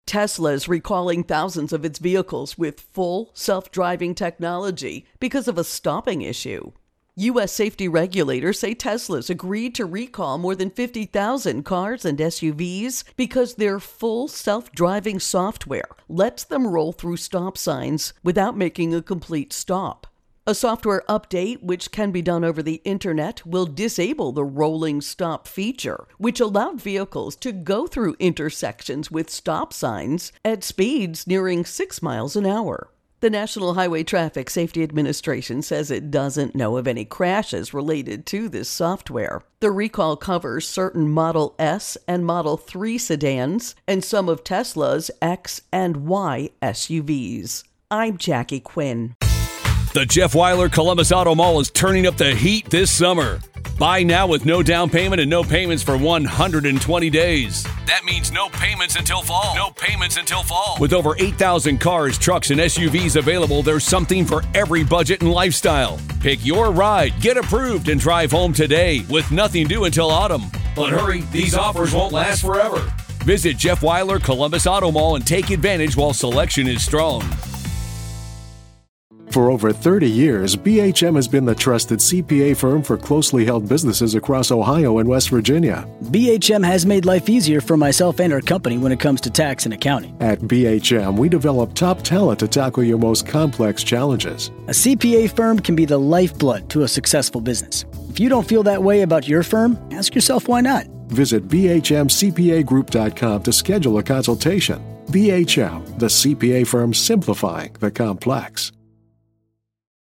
Tesla Full Self Driving Recall Intro and Voicer